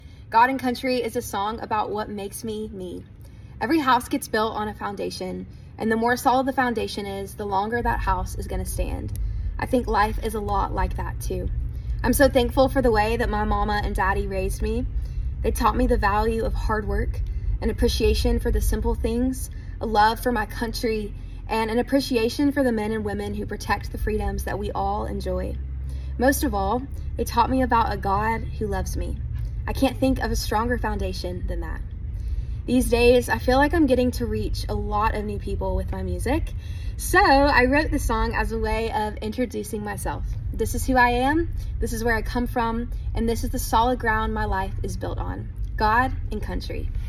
Anne Wilson talks about her song, "God & Country."